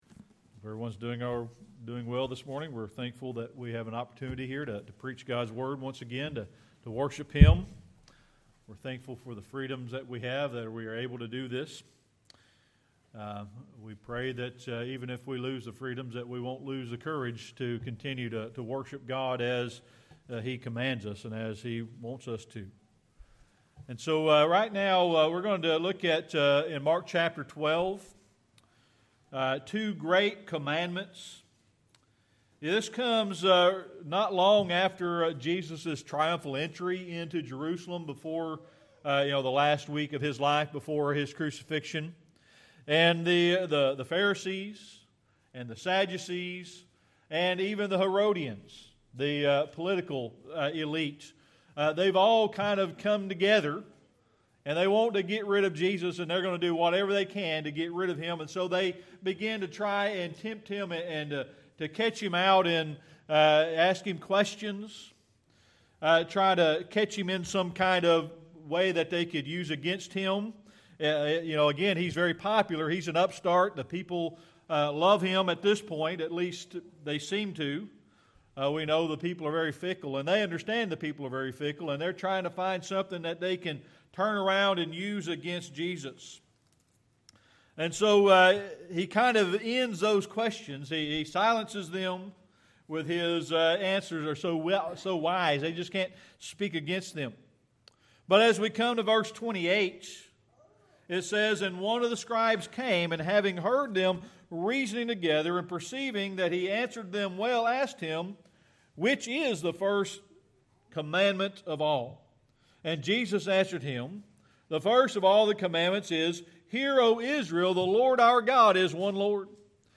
Mark 12:28-34 Service Type: Sunday Morning Worship Looking to Mark 12